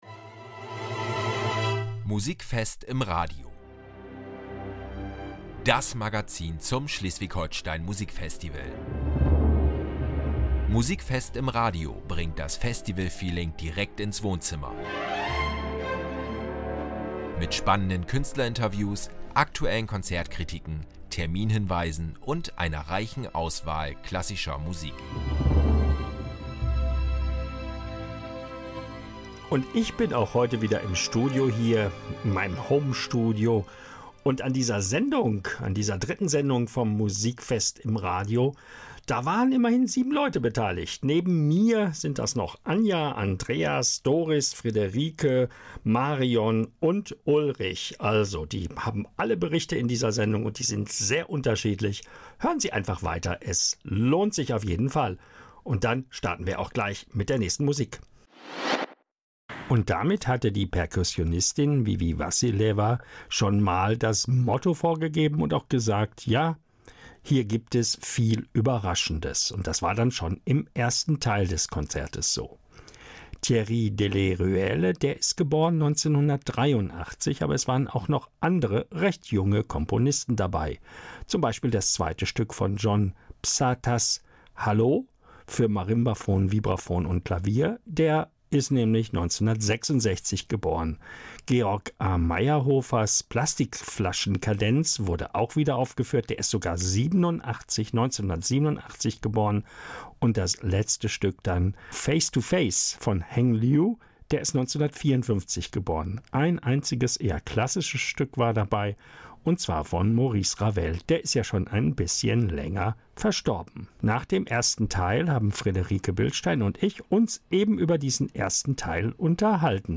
In der dritten Folge von Musikfest im Radio teilen Redaktionsmitglieder ihre ganz persönlichen Eindrücke aus den ersten Festival-Konzerten.